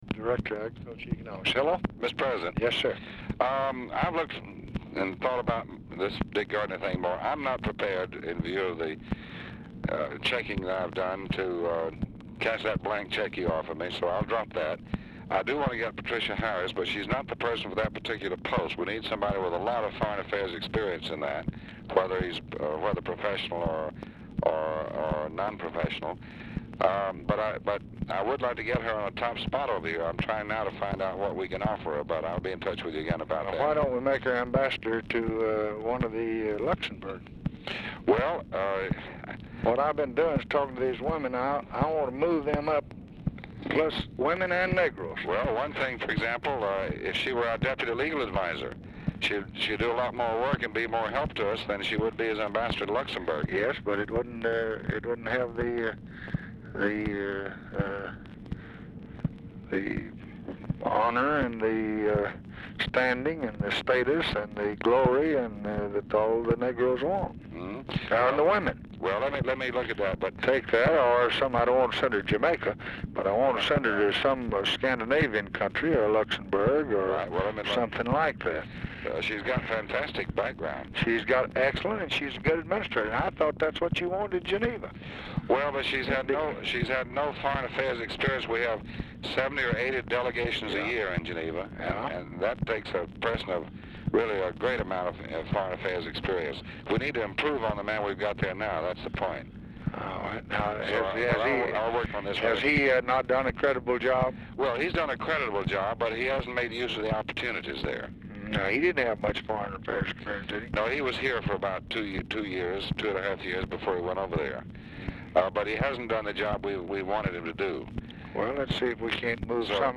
Telephone conversation # 7365, sound recording, LBJ and DEAN RUSK, 4/26/1965, 8:45PM | Discover LBJ
Format Dictation belt
Specific Item Type Telephone conversation